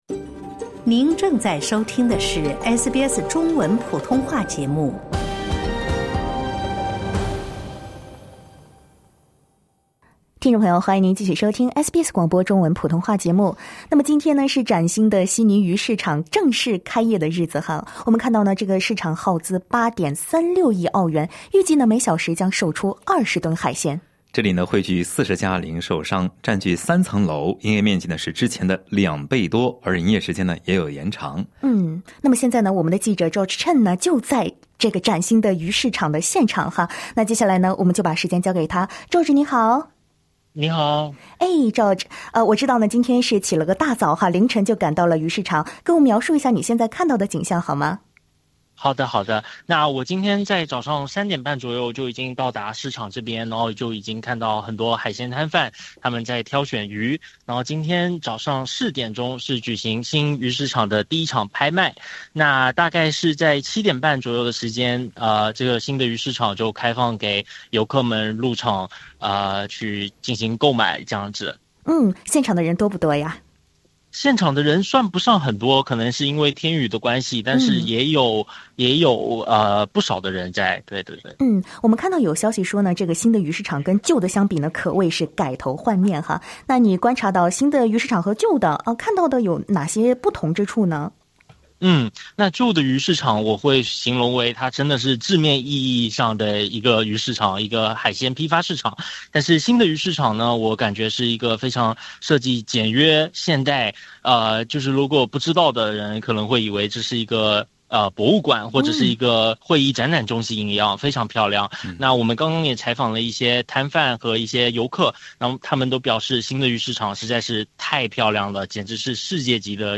现场直击：新悉尼鱼市场开门迎客 “人潮、海鲜与新地标”
先睹为快：新悉尼鱼市场有哪些变化？SBS记者带您现场直击。